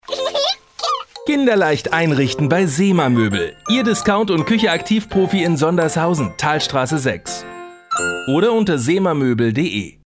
Sprecher deutsch.
Kein Dialekt
Sprechprobe: Industrie (Muttersprache):